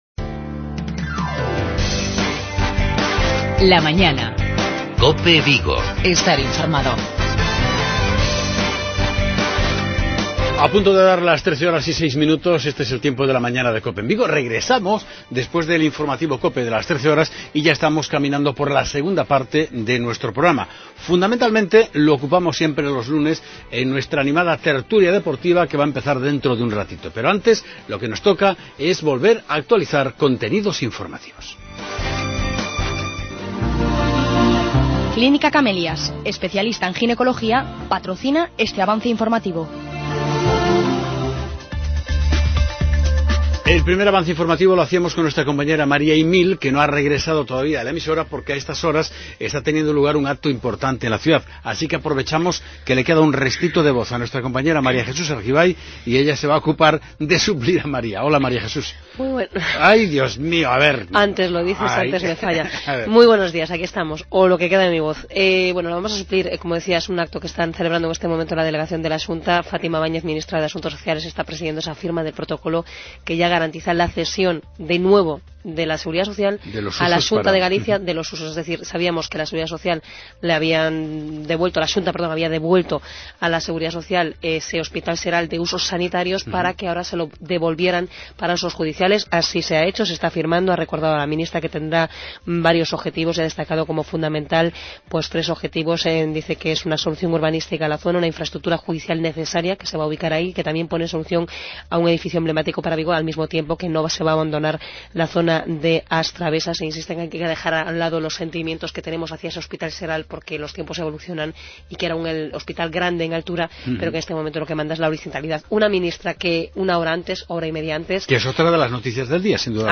Redacción digital Madrid - Publicado el 14 mar 2016, 13:44 - Actualizado 17 mar 2023, 19:38 1 min lectura Descargar Facebook Twitter Whatsapp Telegram Enviar por email Copiar enlace Como cada lunes, en nuestra segunda parte del magazine es tiempo para hablar del Real Club Celta. En la tertulia deportiva analizamos la victoria del equipo por 1-0 en Balaídos ante la Real Sociedad.